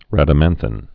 (rădə-mănthĭn, -thīn)